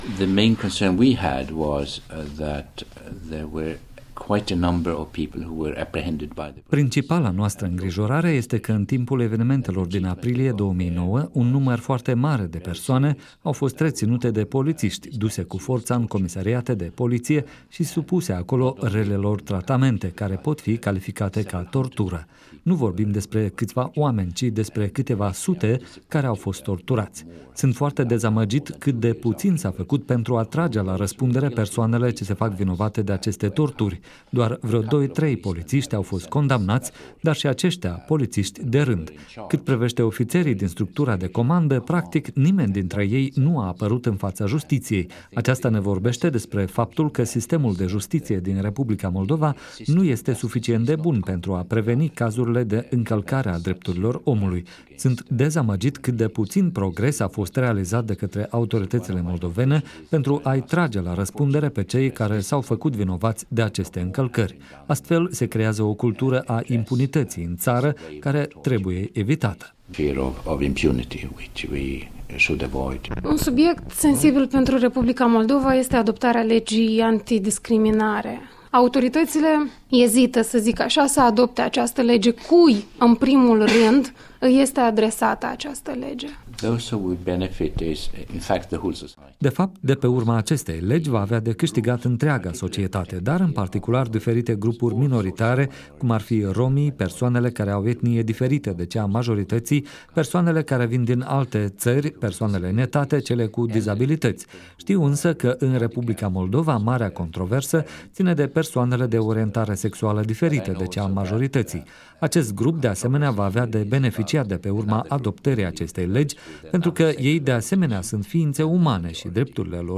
Interviu cu Thomas Hammarberg